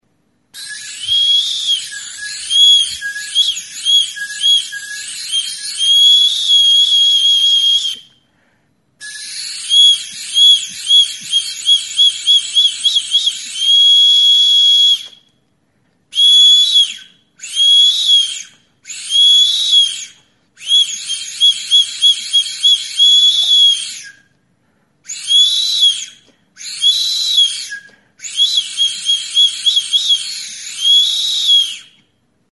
Aerophones -> Flutes -> Piston
Recorded with this music instrument.
Flauta; Pistoi flauta
Tapoi hau sartu eta atereaz tonu aldaketa ematen ditu.